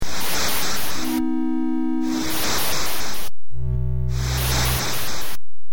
Besides the music, there are the sounds of the battle that is more or less constantly raging around you in the game. I captured most of the sound effects, which are contributing so much to the atmosphere and gameplay.
blaster.mp3